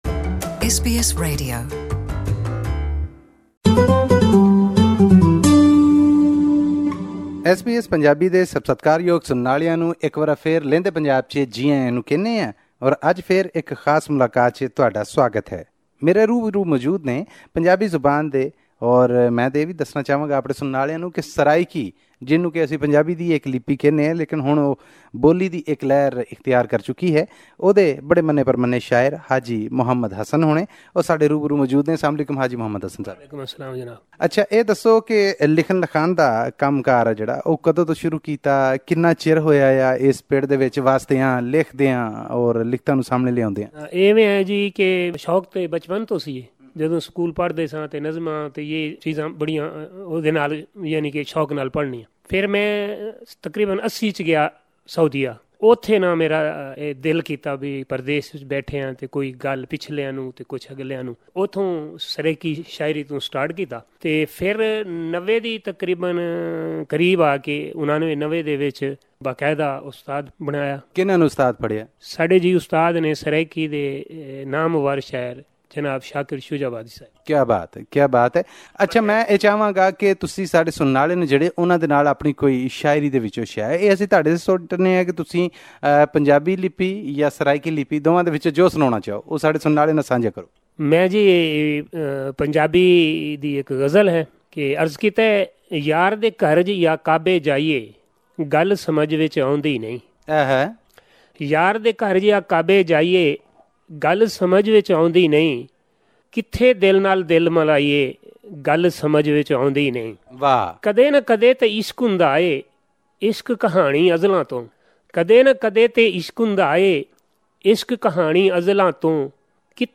Every fortnight, we interview a noted writer or artiste from Lehnda Punjab.